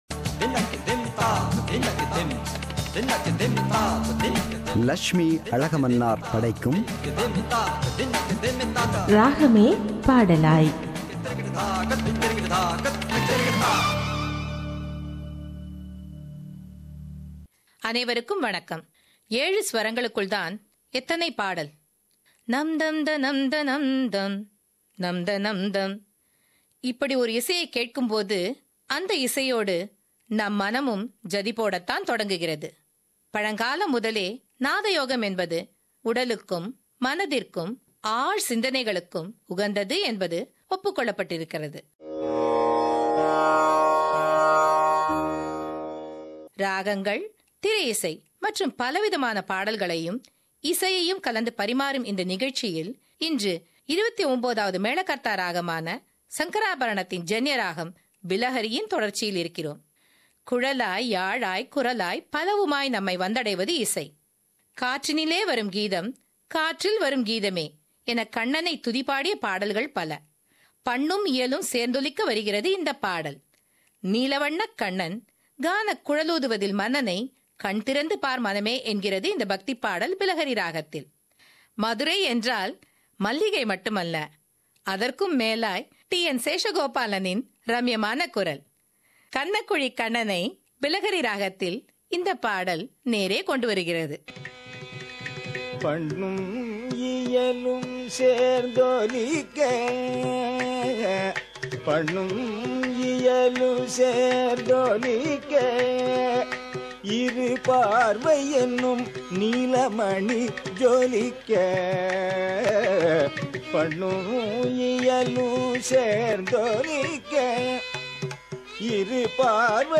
She presents the episode on Bilahari raga.